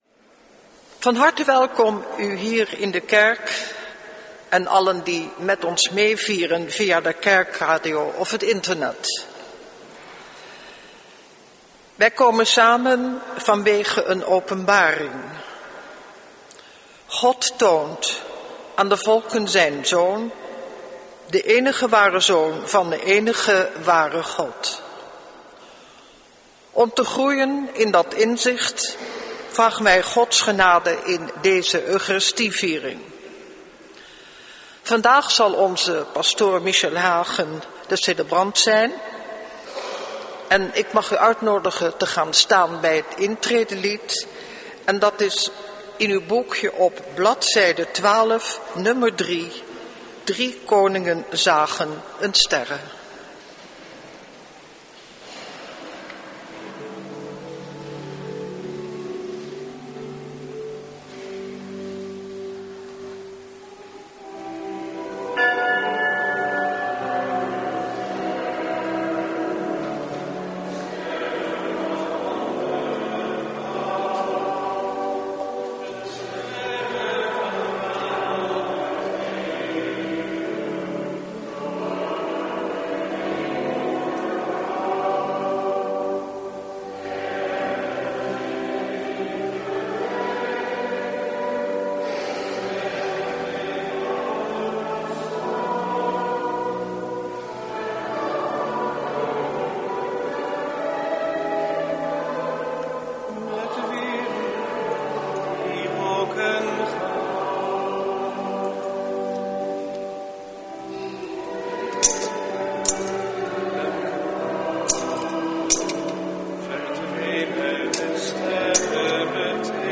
Preek Hoogfeest Openbaring des Heren, Driekoningen, jaar A, 7/8 januari 2017 | Hagenpreken